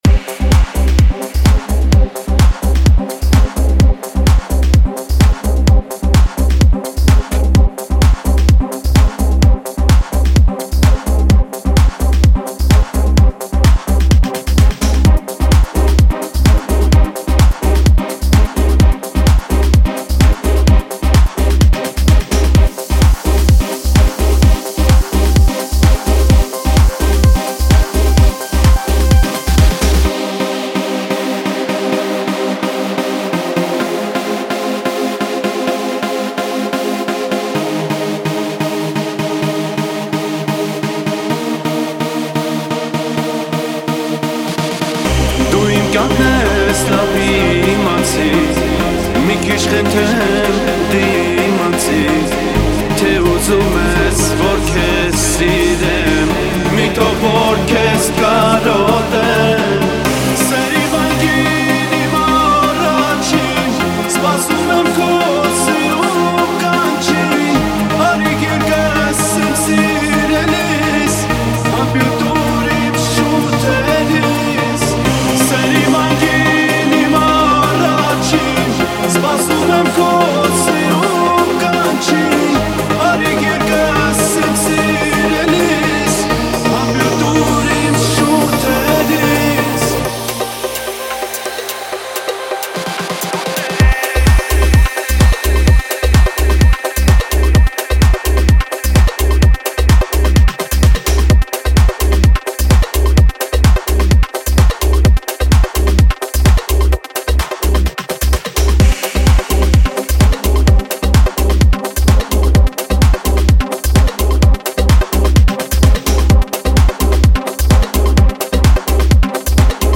Erger 2017, Армянская музыка